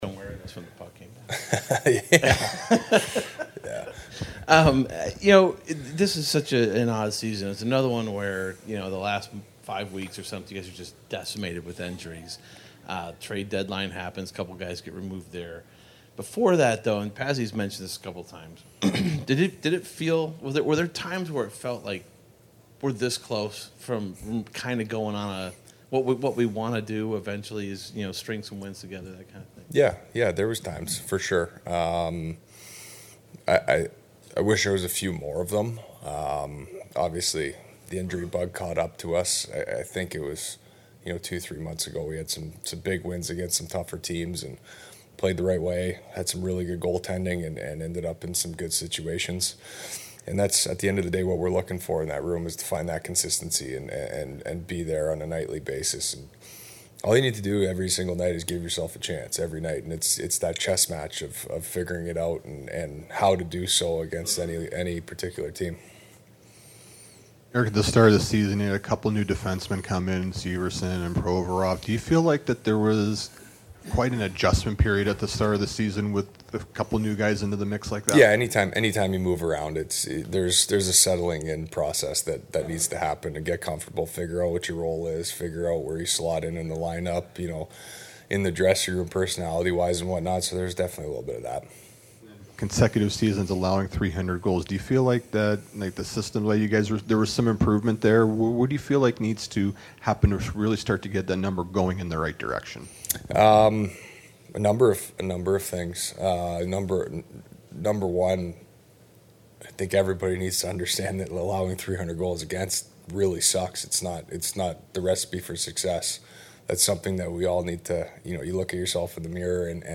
Columbus Blue Jackets Season Ending Exit Interviews: Alternate captain & defenseman Erik Gunbranson says, “Team is not far off from playoffs”
Erik Gudbranson CBJ defenseman 2023-24 Blue Jackets Season-Ending Exit Interviews April 2024.mp3